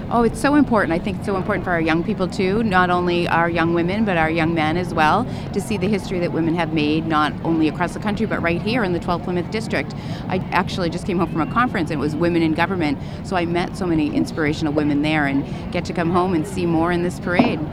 State Rep. Kathy LaNatra was among local officials at the parade: